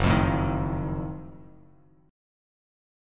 F#mM13 chord